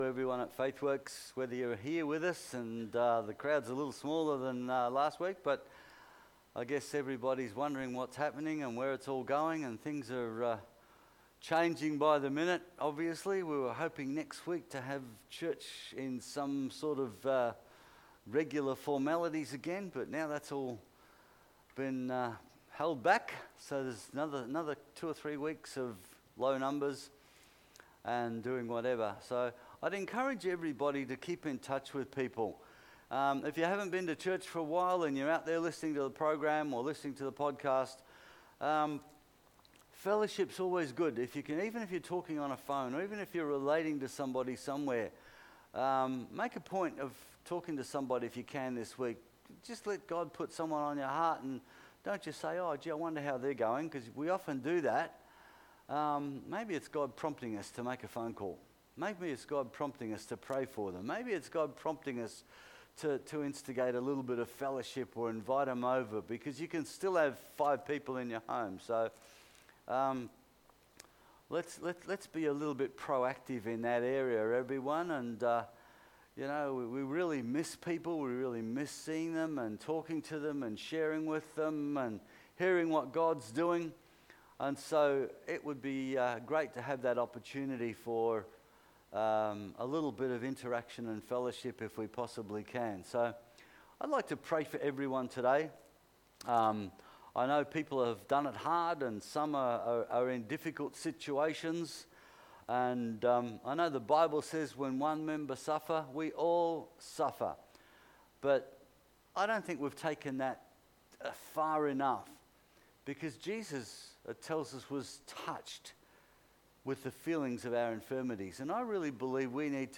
Sunday Service June 21st 2020